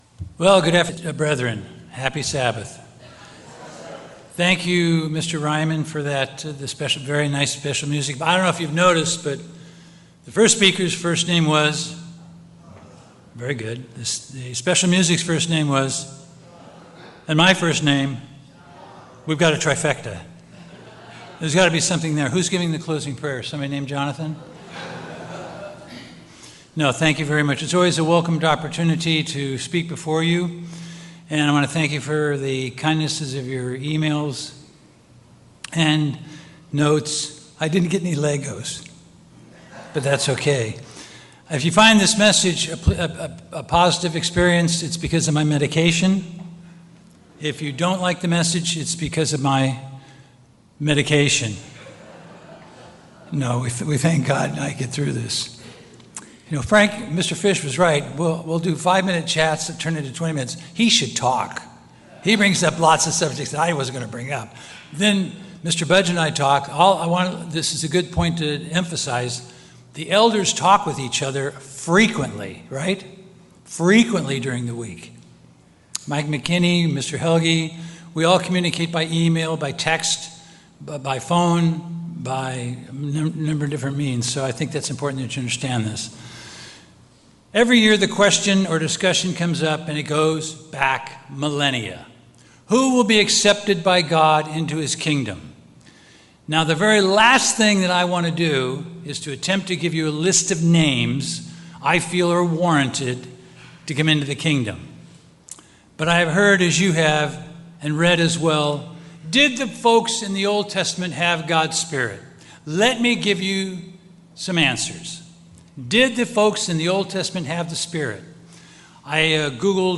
Given in Los Angeles, CA Bakersfield, CA